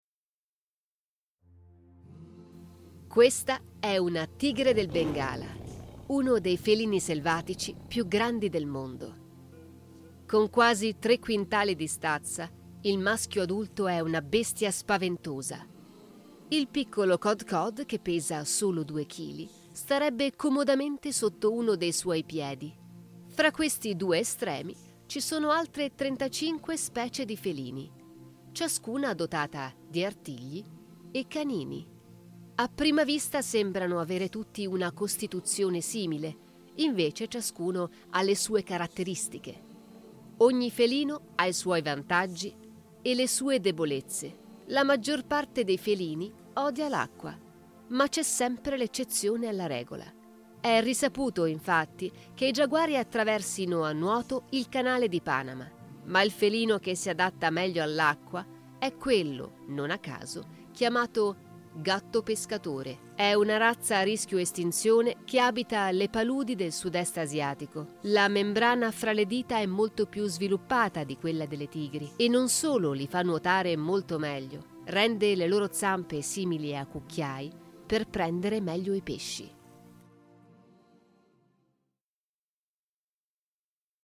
Voice Talent, Speaker, Script Translator and Adaptor, Actress, Teacher
Sprechprobe: Industrie (Muttersprache):
I have a soundproof home studio with professional sound absorber panels, Rode NT USB microphone and Vocal Booth Kaotica Eyeball